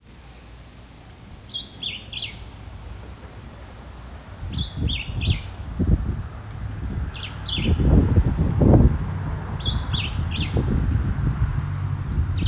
Black-whiskered Vireo
Terrebonne